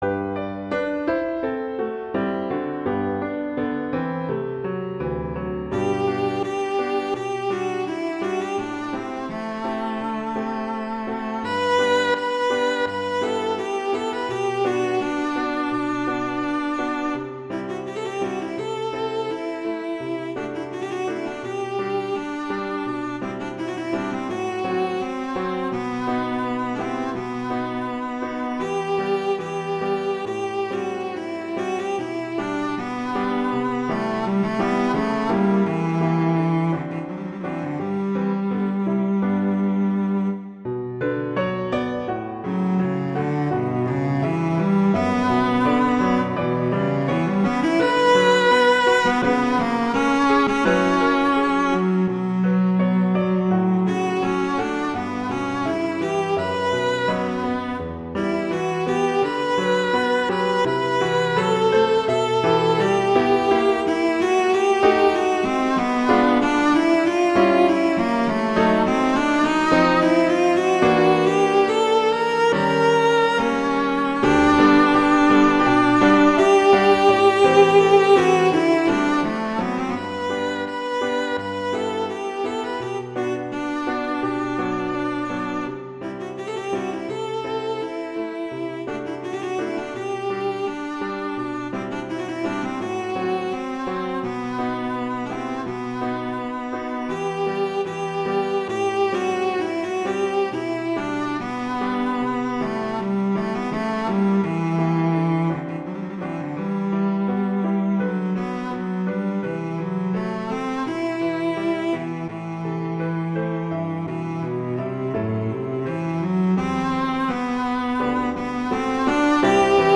classical
G major
♩=84 BPM